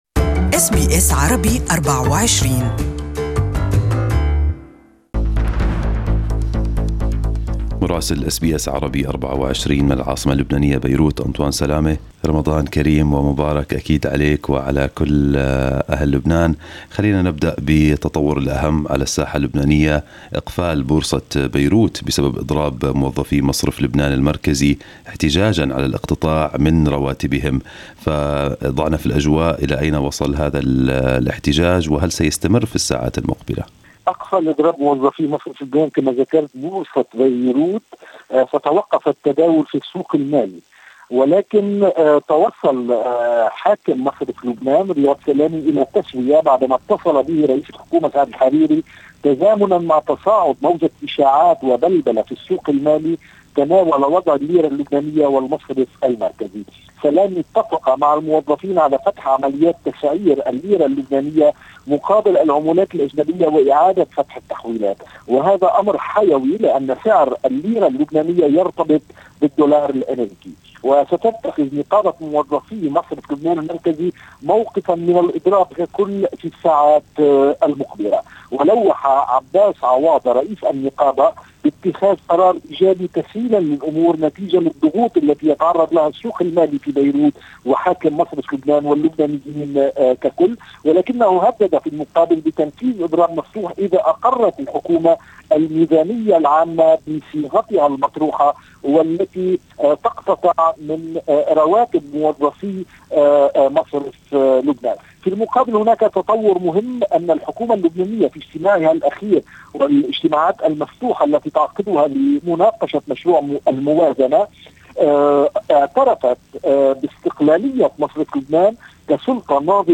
Our correspondent in Beirut has the details